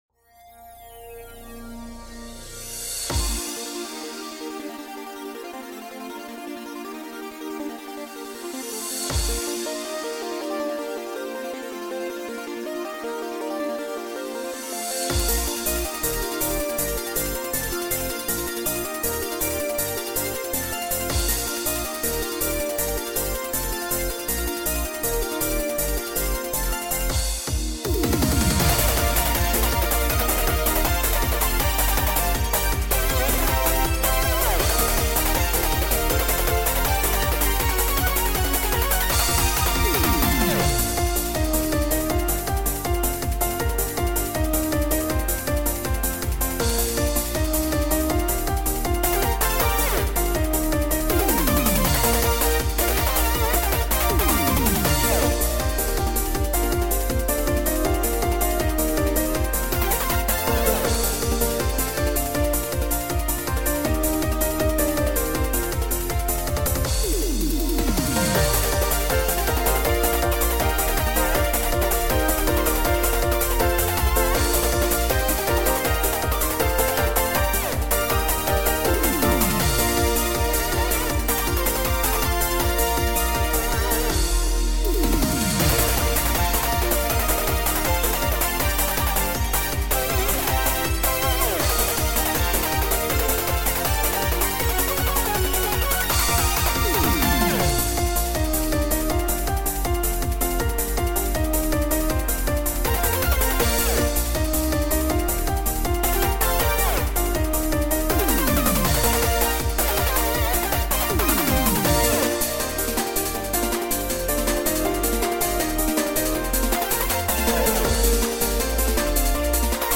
BPM: 160 You can make a simfile for Stepmania if you want.
genre:eurobeat